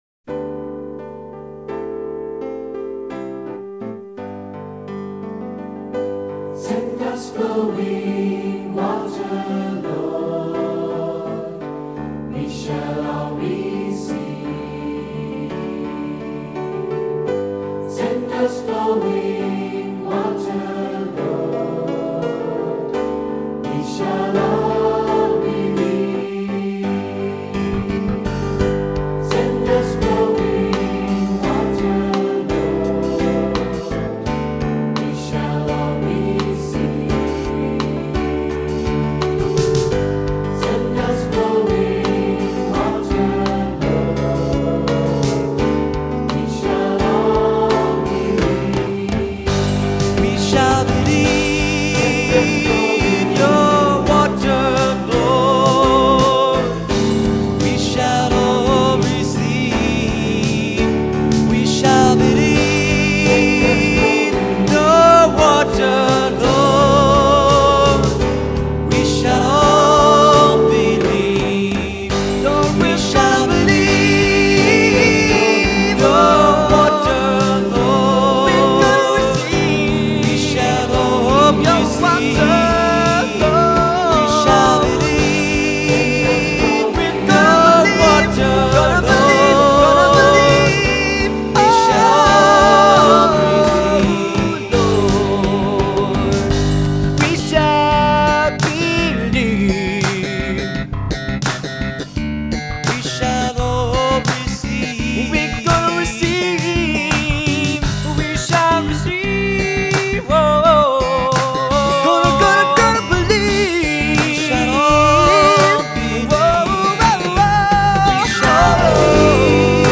Voicing: Assembly,Cantor